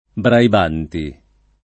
[ braib # nti ]